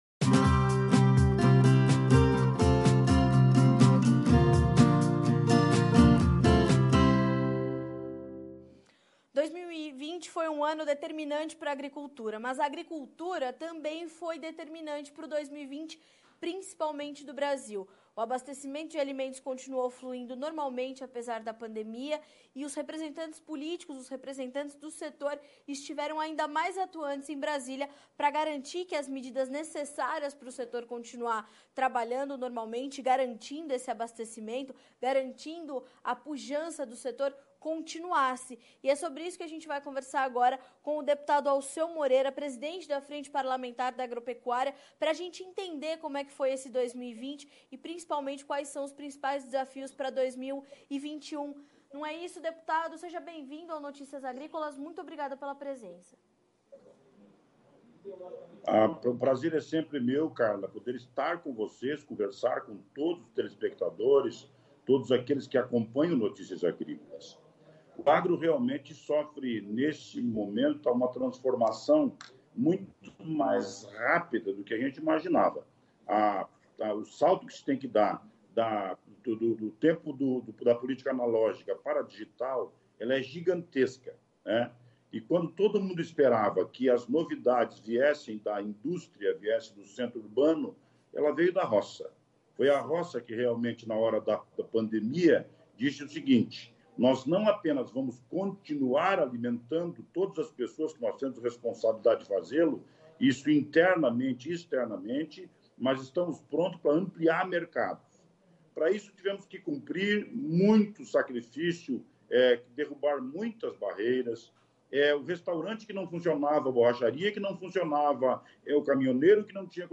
Em entrevista ao Notícias Agrícolas